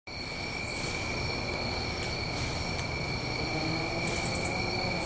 Recorded over a neighbors house sound effects free download